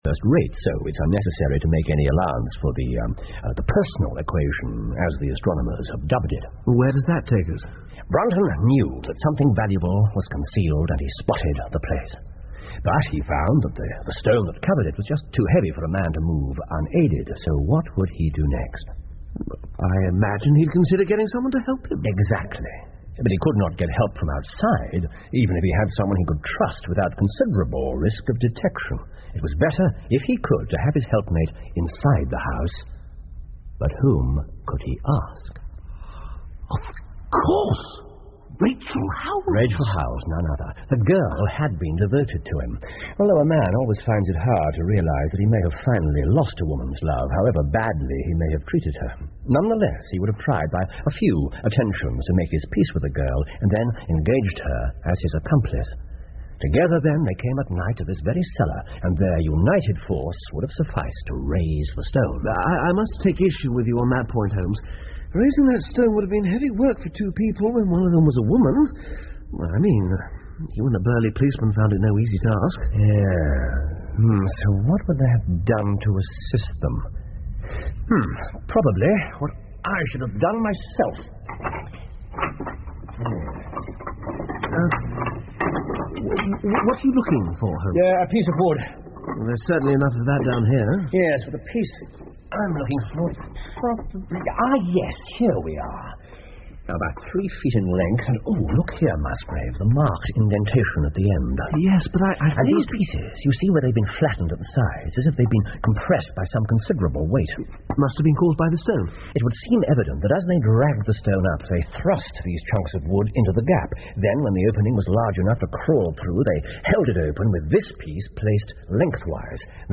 福尔摩斯广播剧 The Musgrave Ritual 8 听力文件下载—在线英语听力室